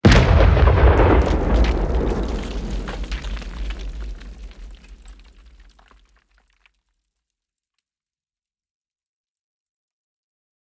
snake-earth-hit.ogg